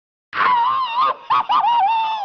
/sound/quake/female/